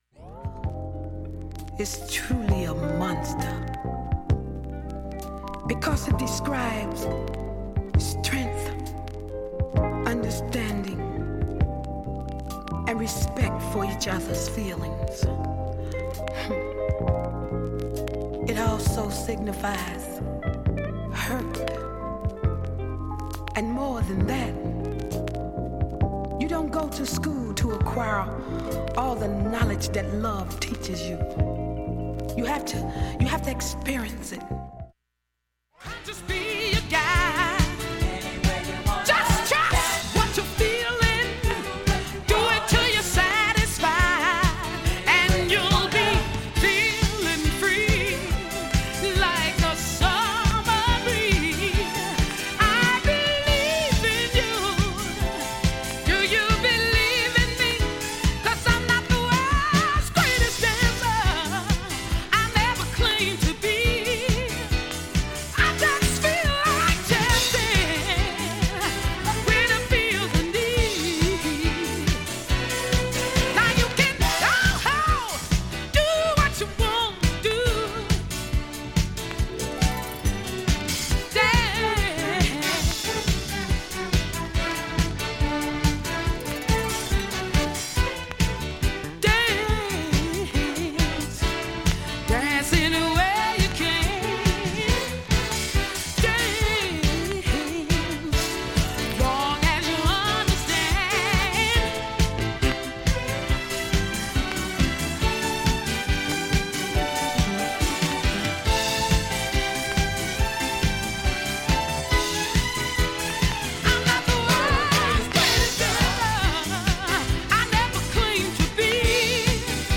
A面は全く問題無し、音質良好です。
B-1後半喋りの部でかすかなプツが１６回出ます。
周回プツ出ますがストレスは感じない